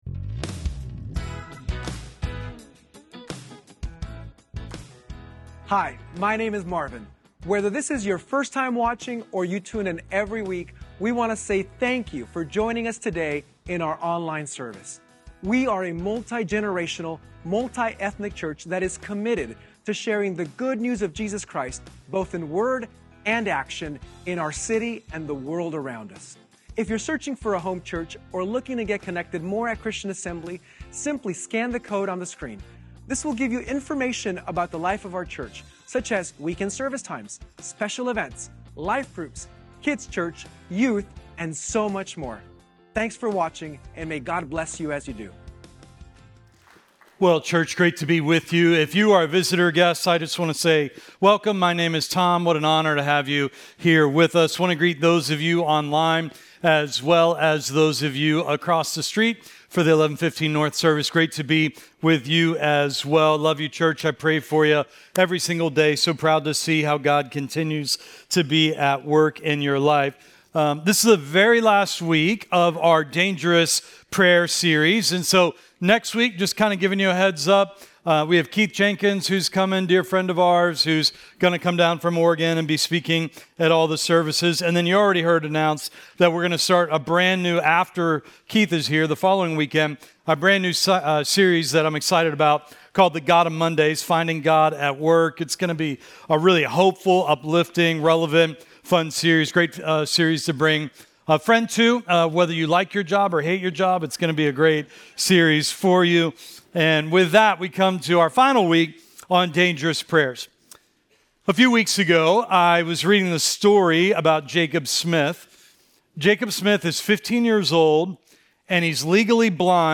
Weekend-Service-2.23.25.mp3